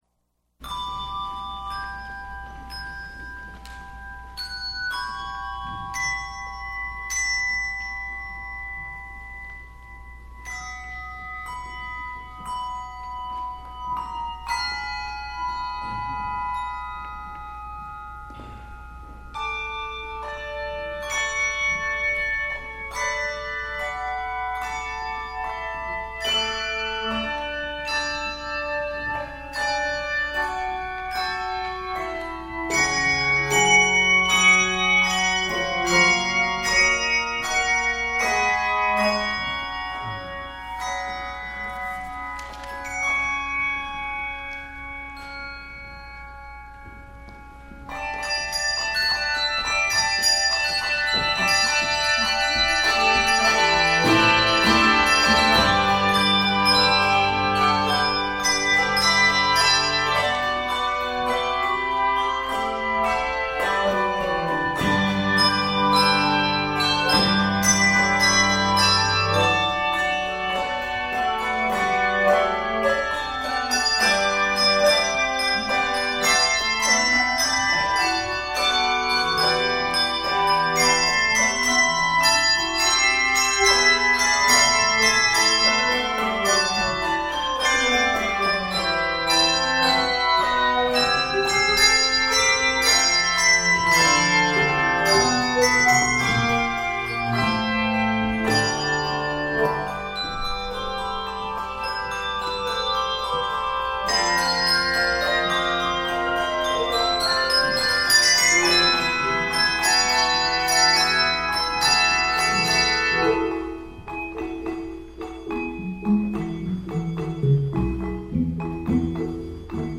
Keys of Ab Major and F Major.
Octaves: 5 or 7